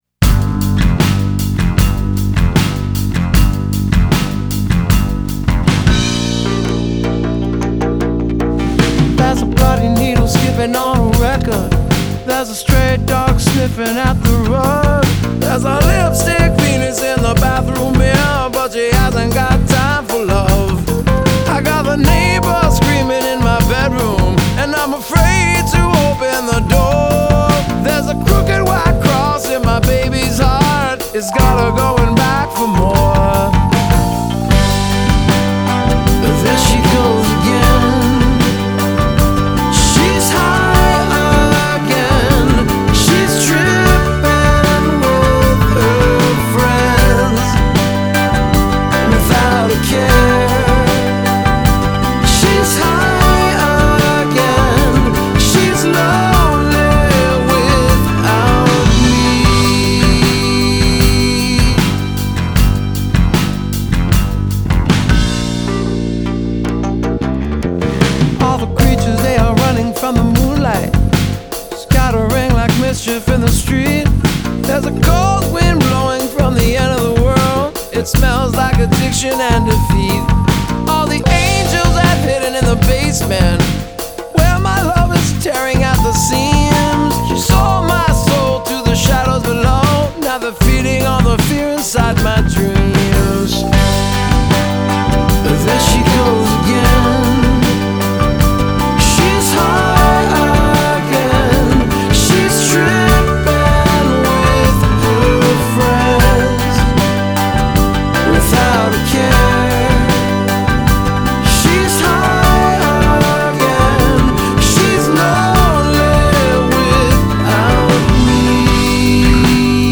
bass-driven, melodic
the Canadian rockers